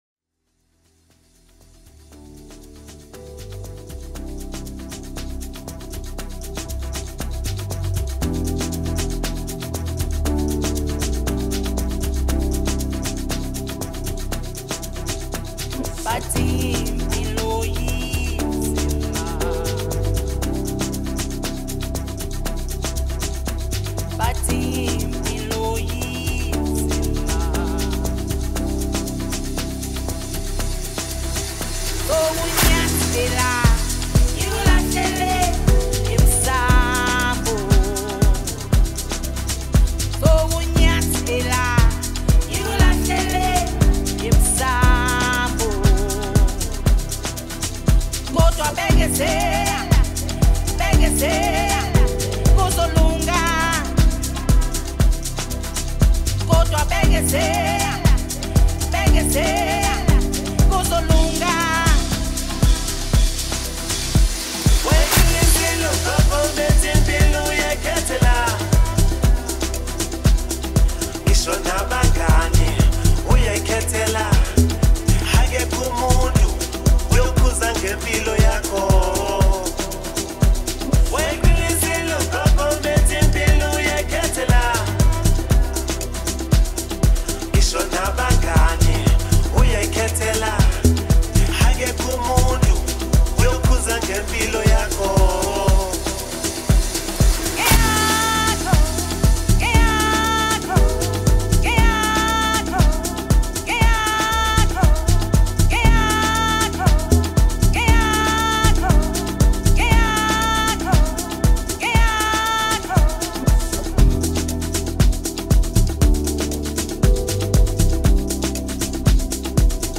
Amapiano
South African singer-songsmith